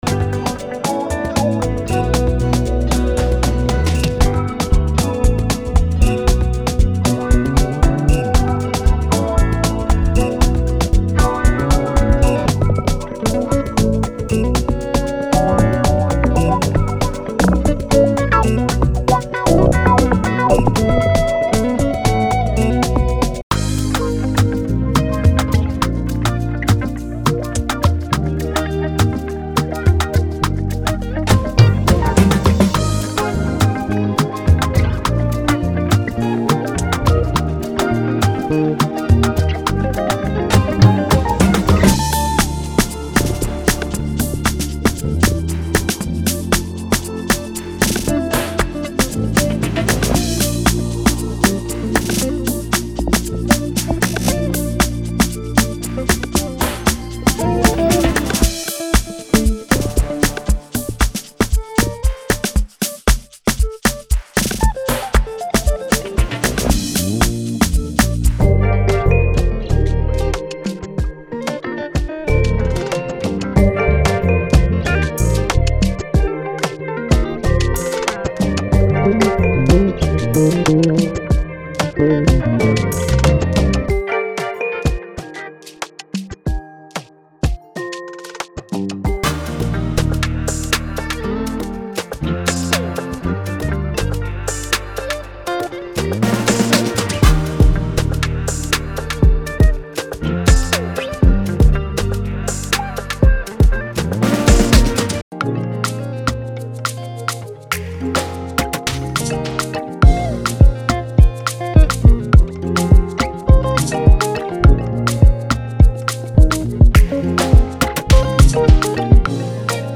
– 130+ Drum & Percussion Loops
– 40+ Guitar Loops
– 10+ Bass Guitar Loops